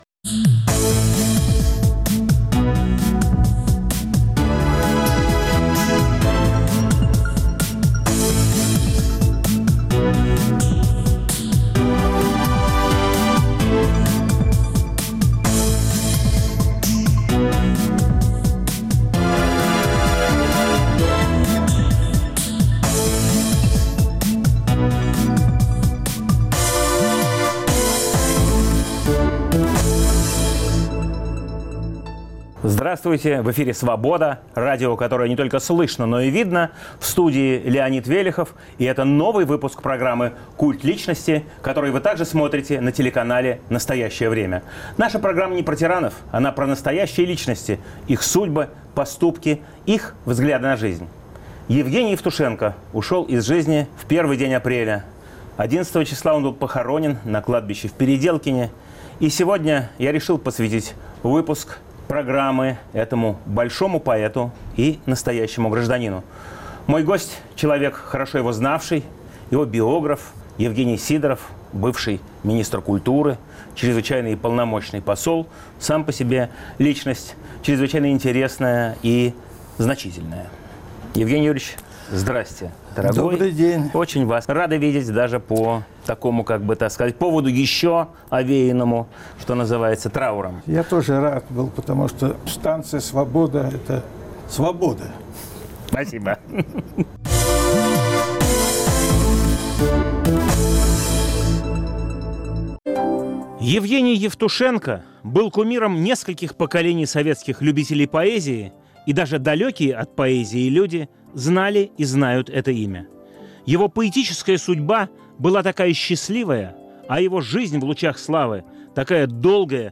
Новый выпуск программы о настоящих личностях, их судьбах, поступках и взглядах на жизнь. О Евгении Евтушенко - друг и биограф поэта, министр культуры России в 1992-97 гг. Евгений Сидоров.